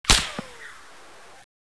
weapon_whizz4.wav